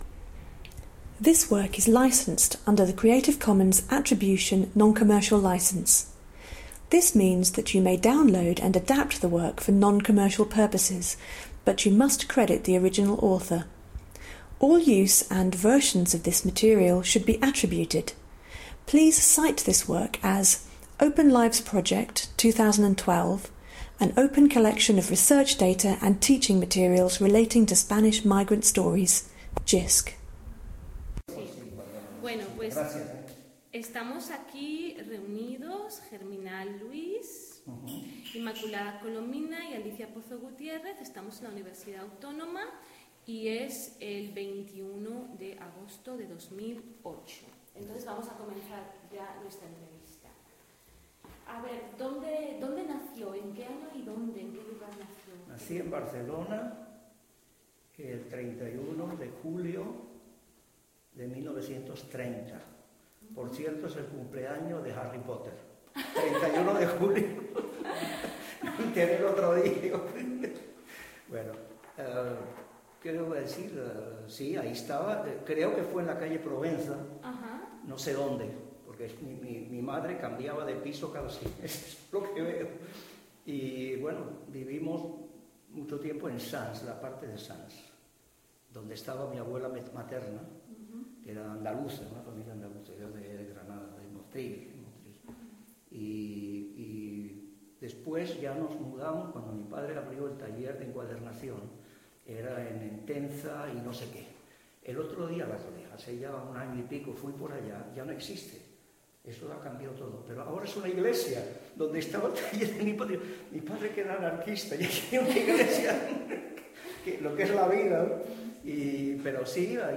OpenLIVES Spanish emigre interviews